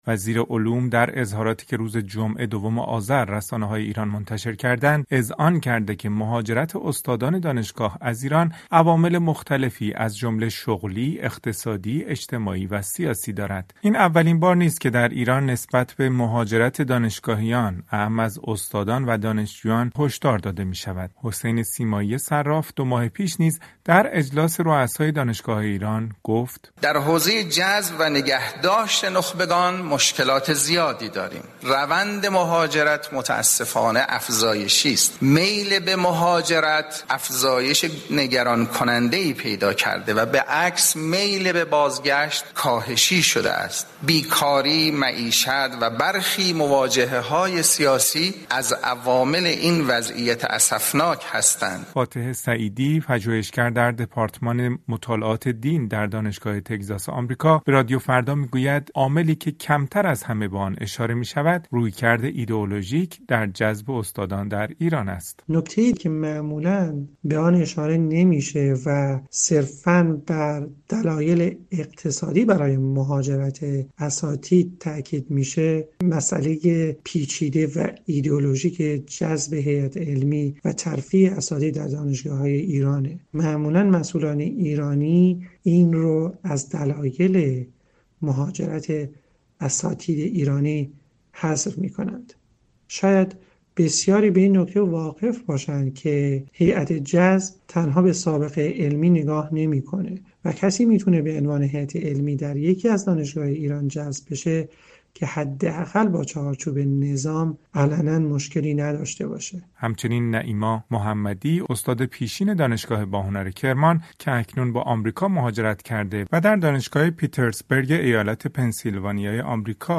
گزارش رادیویی «مهاجرت استادان» از ایران